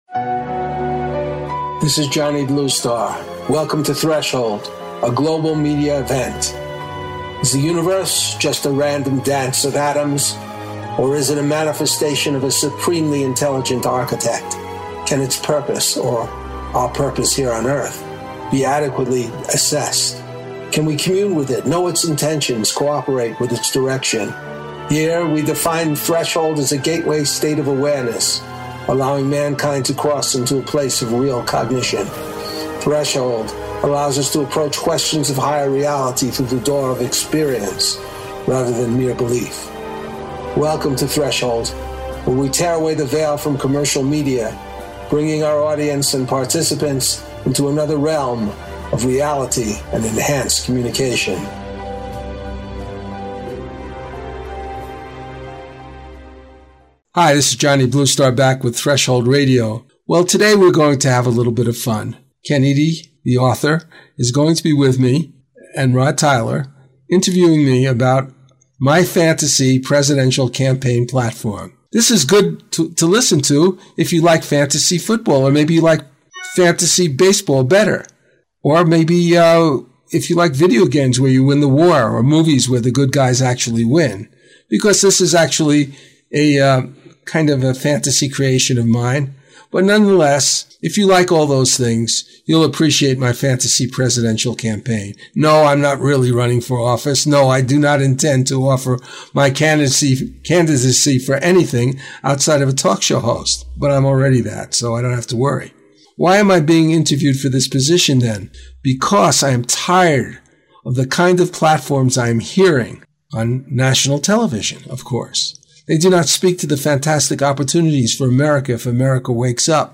In this extensive interview with attorney/novelist